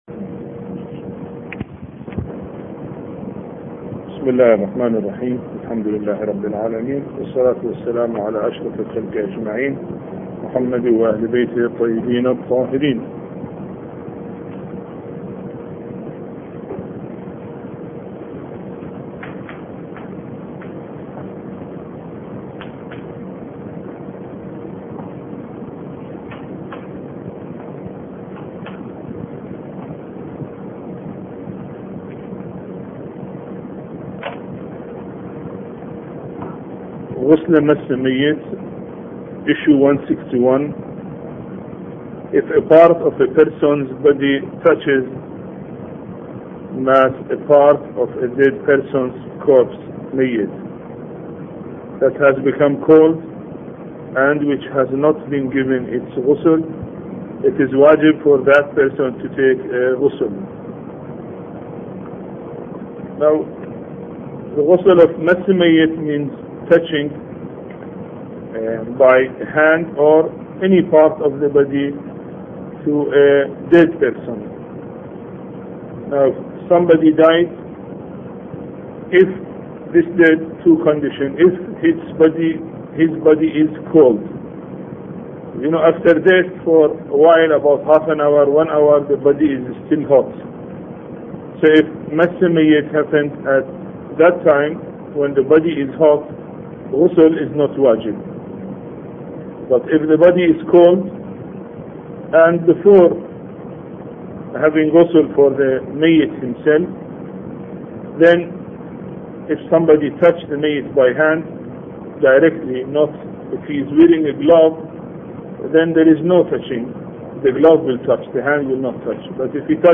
A Course on Fiqh Lecture 8